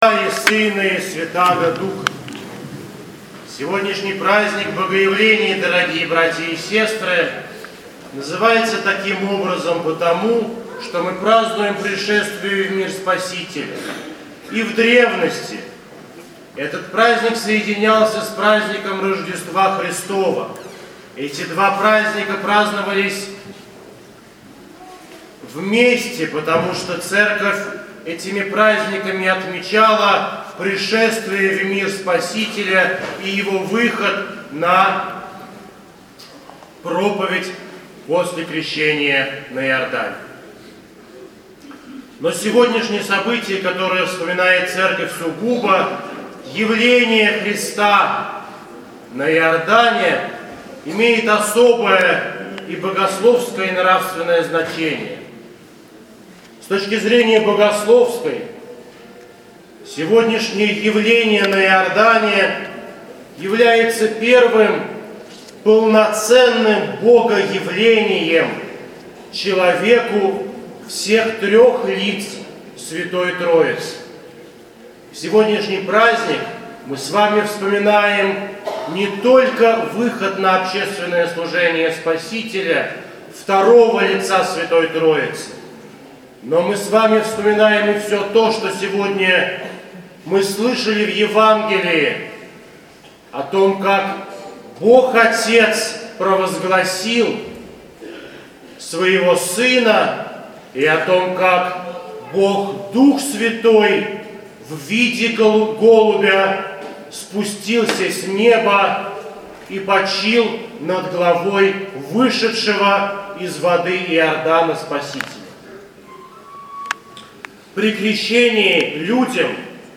Проповедь настоятеля храма в праздник Крещения Господня